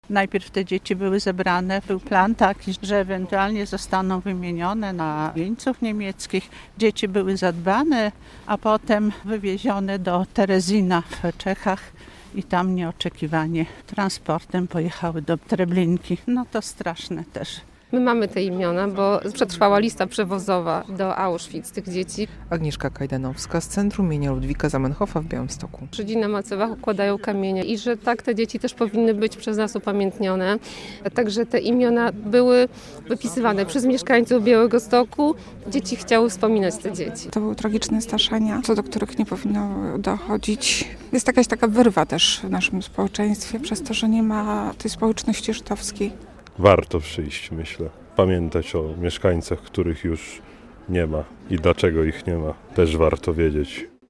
relacja
Uroczystości rozpoczęły się przy jednej z symbolicznych bram getta, przy ulicy Fabrycznej w Białymstoku.